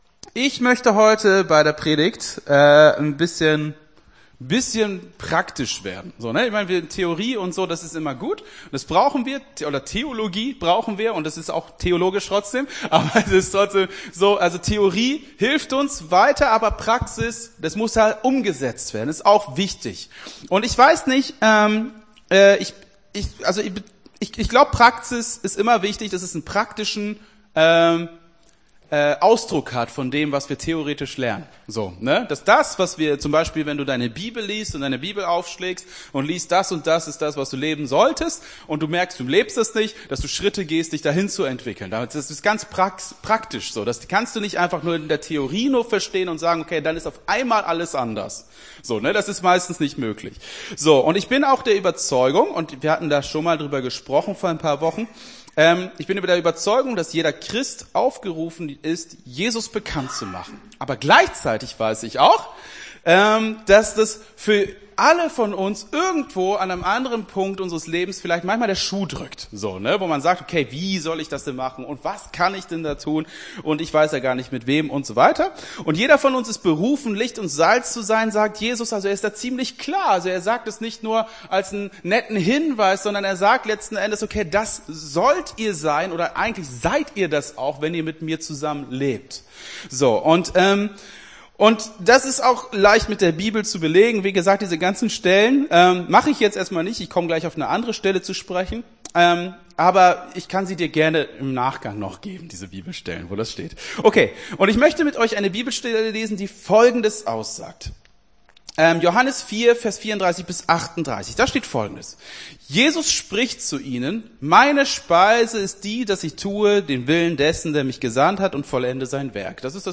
Gottesdienst 07.05.23 - FCG Hagen